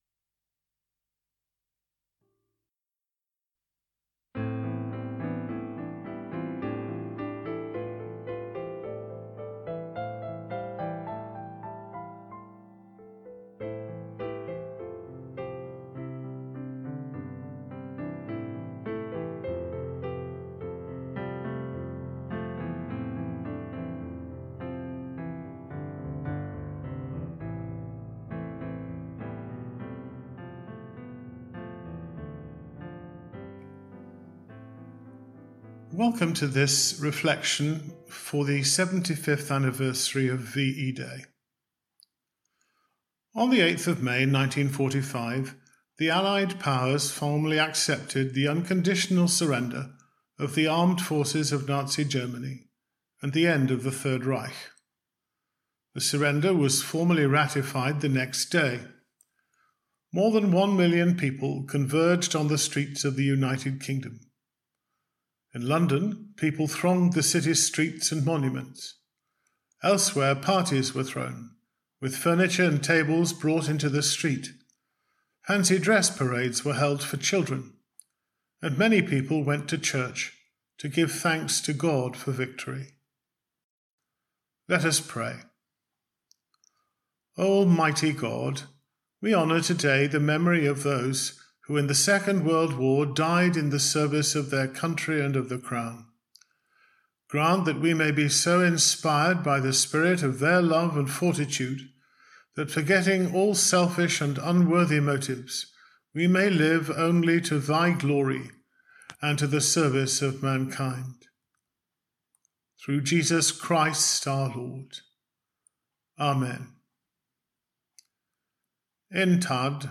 Reflection